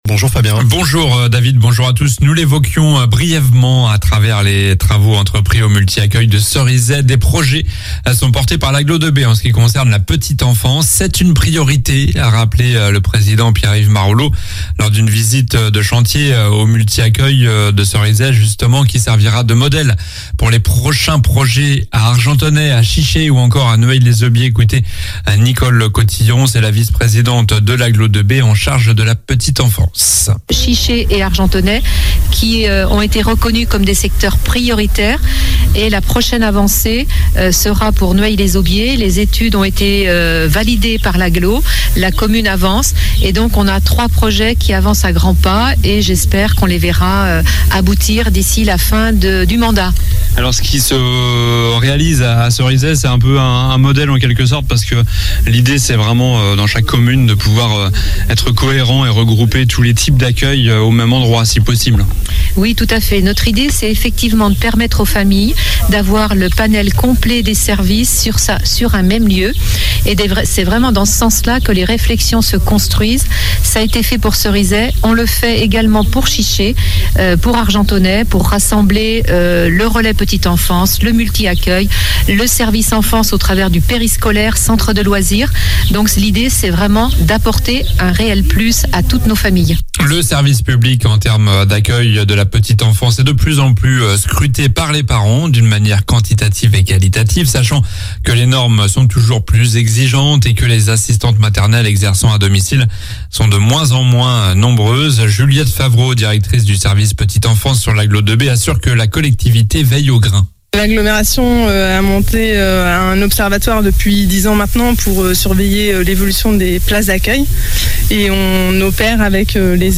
Journal du jeudi 13 juillet (midi)